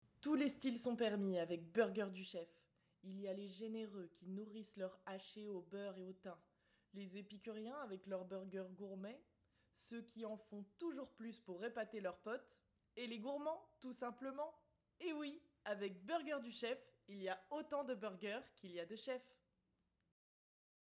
Voix off
25 - 35 ans - Contralto Mezzo-soprano